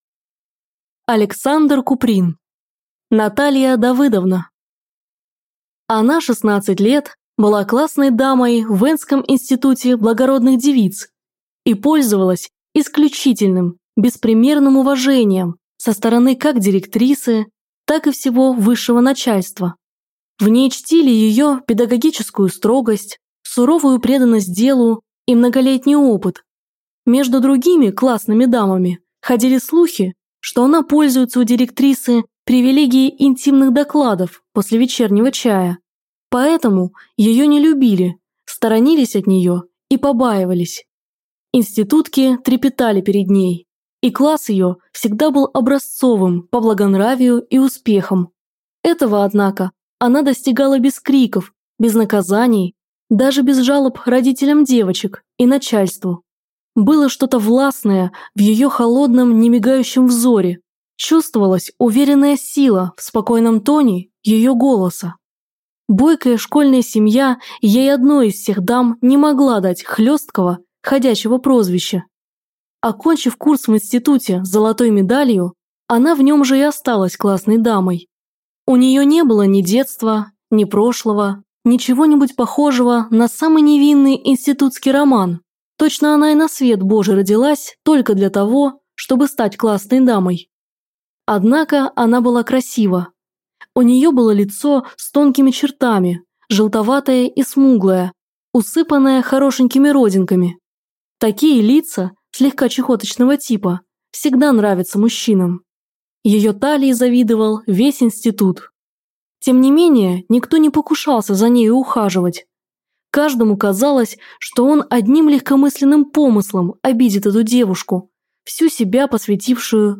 Аудиокнига Наталья Давыдовна | Библиотека аудиокниг